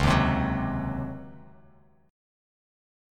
C#M7sus2sus4 chord